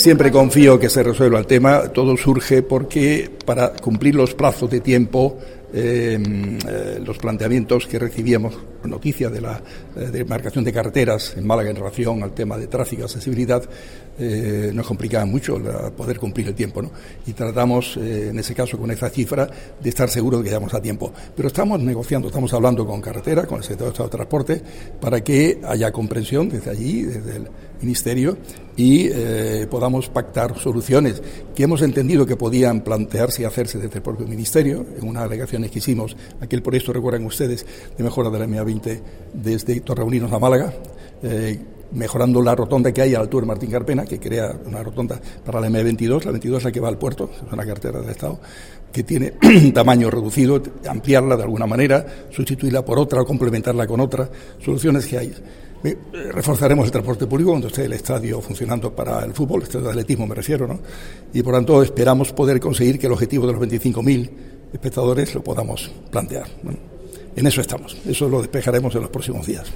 «Todo surge para cumplir los plazos de tiempos en los planteamientos que recibíamos de Carreteras en relación al tráfico. Nos complicaba mucho. Con esa nueva cifra nos asegurábamos llegar a tiempo. Estamos negociando con el sector de Transporte para que con el Ministerio podamos pactar soluciones«, aseguraba el alcalde de Málaga en el canutazo concedido a la prensa.